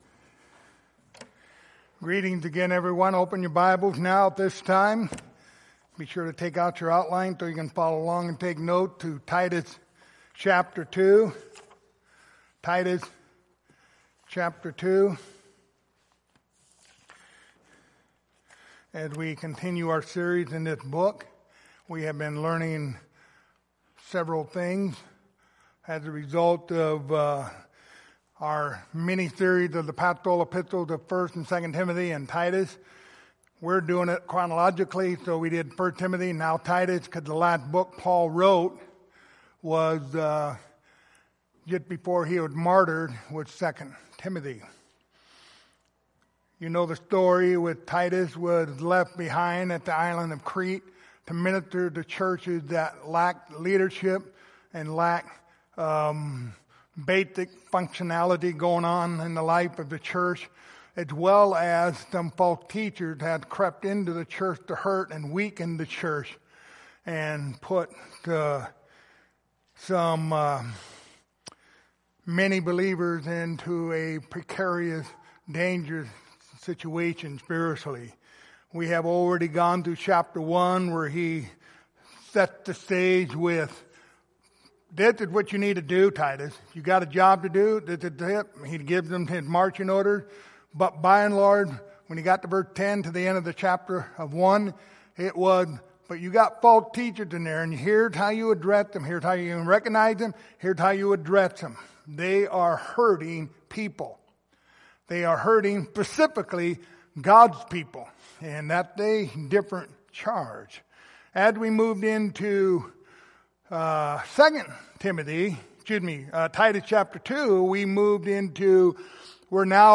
Pastoral Epistles Passage: Titus 2:13-14 Service Type: Sunday Morning Topics